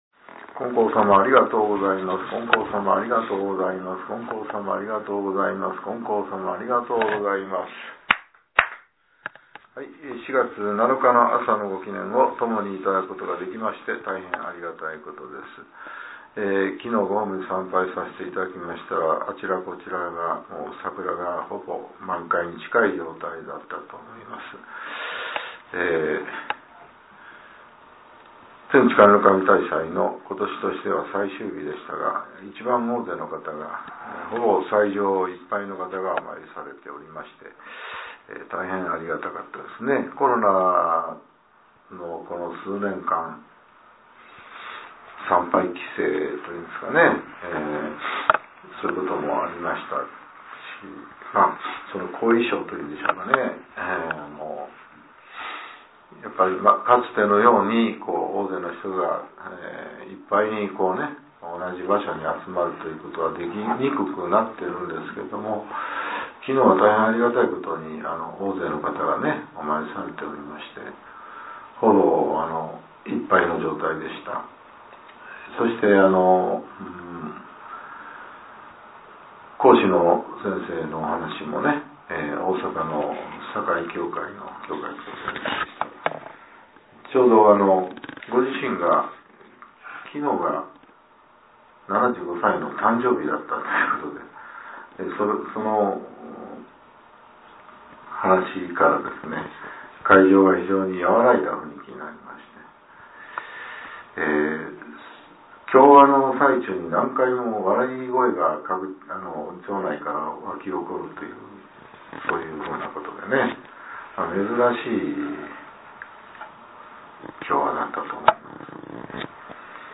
令和７年４月７日（朝）のお話が、音声ブログとして更新されています。